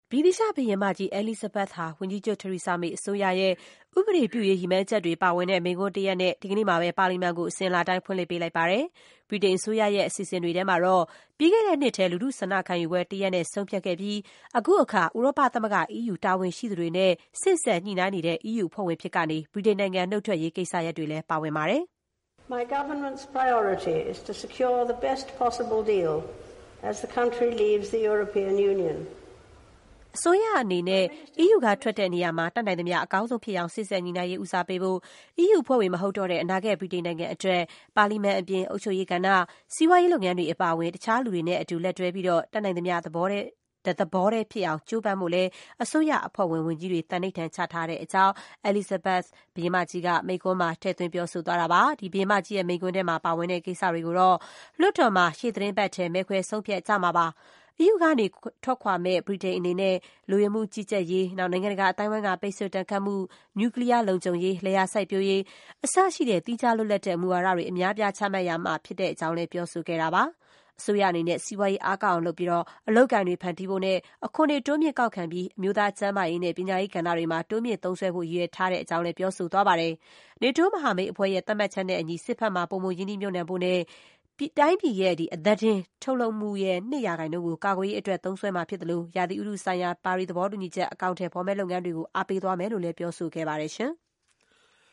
ဗြိတိသျှ ဘုရင်မကြီး Elizabeth လွှတ်တော်မှာ မိန့်ခွန်းပြော